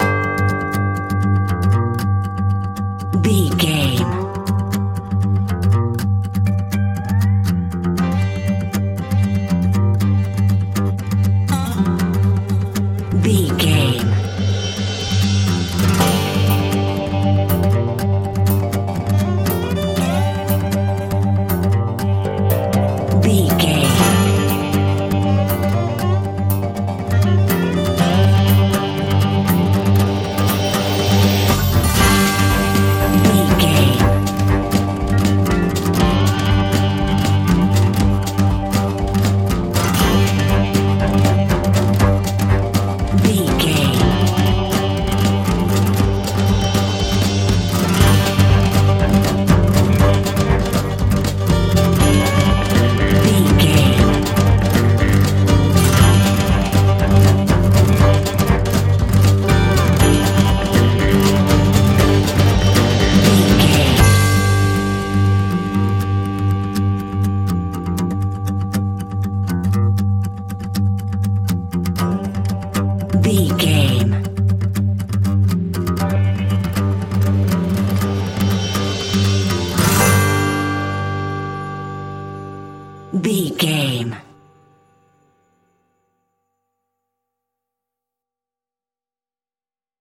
Epic / Action
Aeolian/Minor
powerful
foreboding
driving
energetic
acoustic guitar
electric guitar
drums